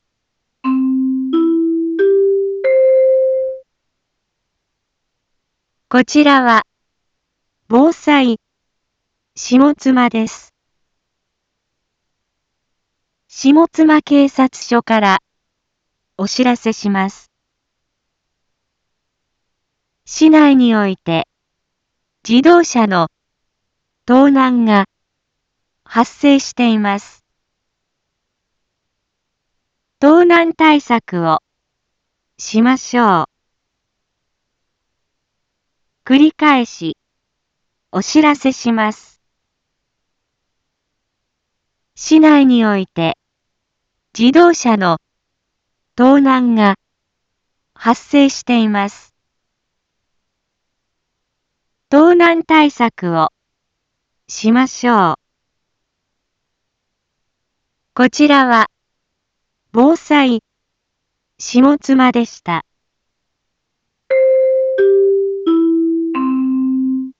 一般放送情報
Back Home 一般放送情報 音声放送 再生 一般放送情報 登録日時：2022-03-05 12:31:11 タイトル：自動車盗難への警戒について インフォメーション：こちらは、防災下妻です。